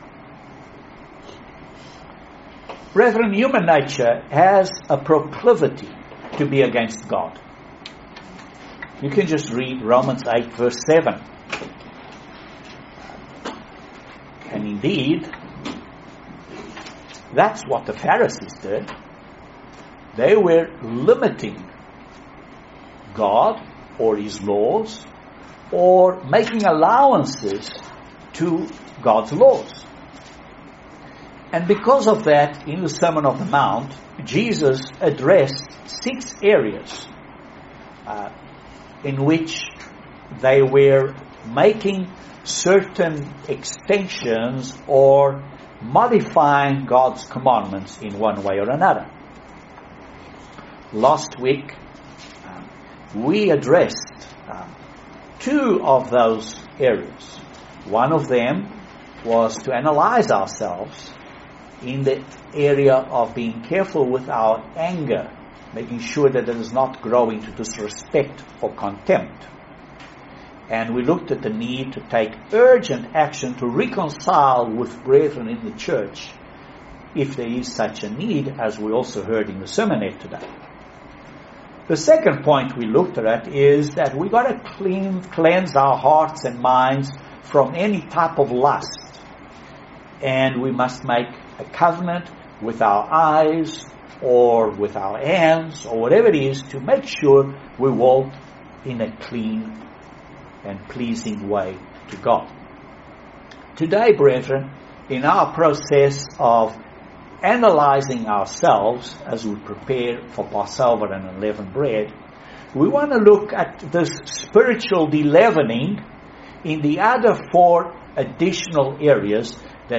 Great Sermon message on analyzing ourselves for Passover and days of unleavened bread.